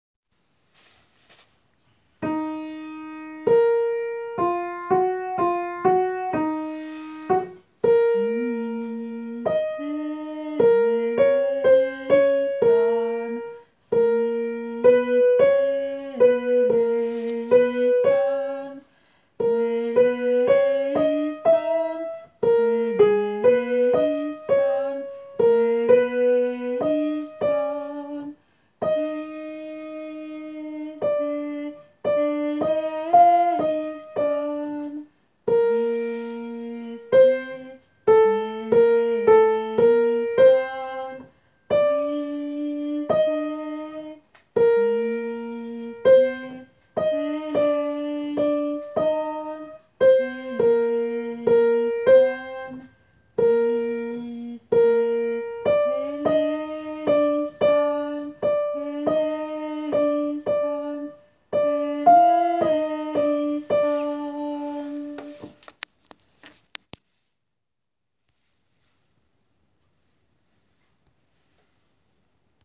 Tenor
gounod.christe.tenor_.wav